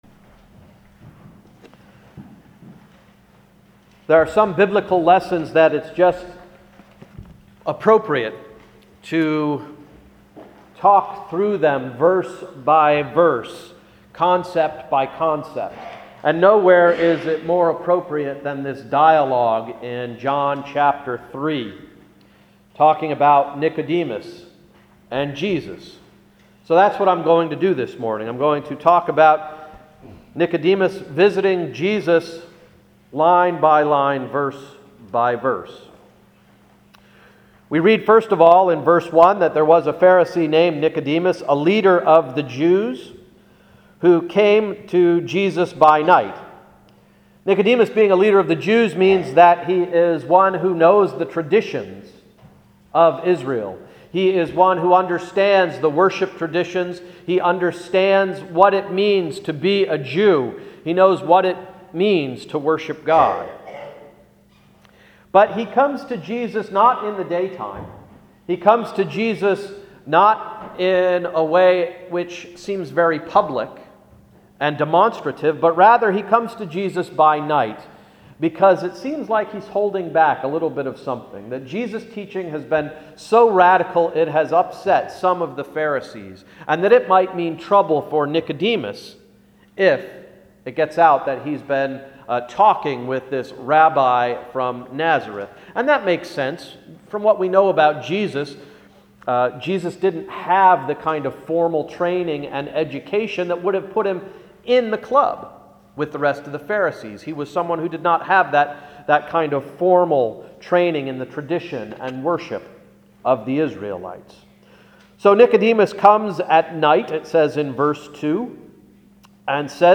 Sermon of March 20, 2011–“Boldly Being Born”